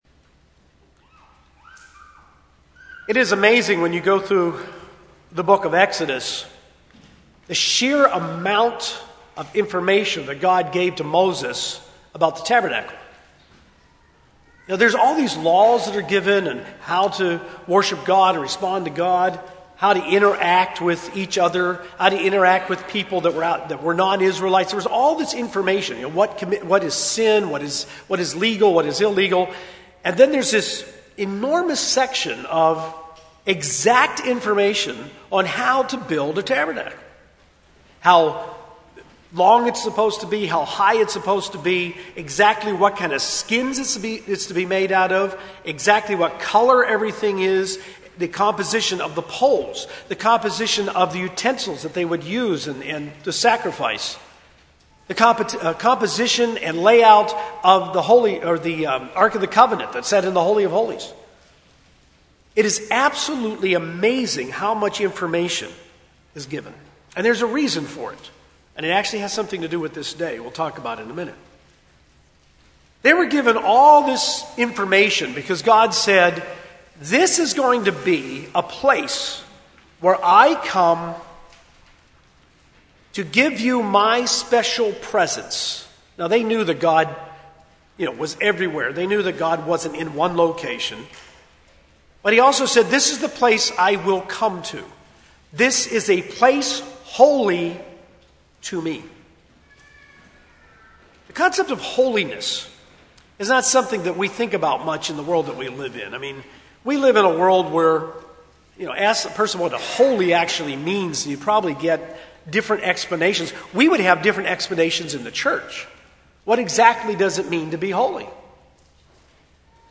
Why it's so important to understand God's holiness, and how our lifelong pursuit to becoming holy like God is something to be relentlessly dedicated to (including day-to-day examples). This message was given on the Feast of Pentecost 2018.